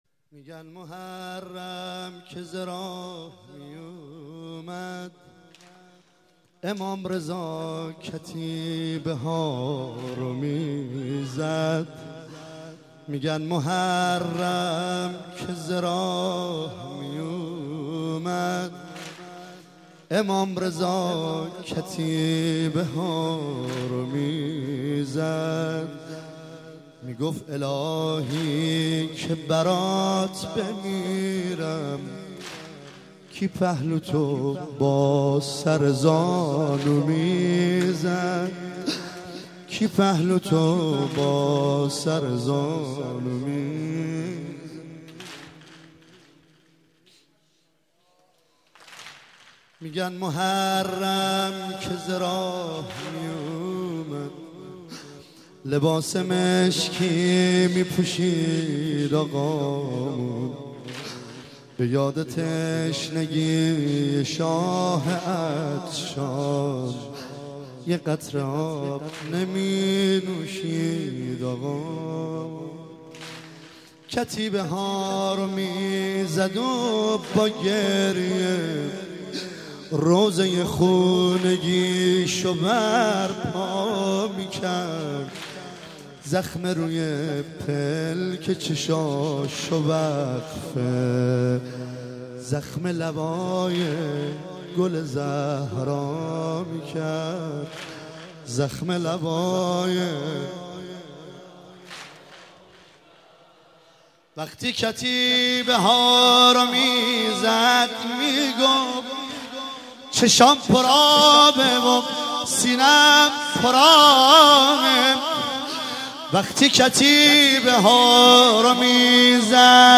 شب دوم محرم 94 واحد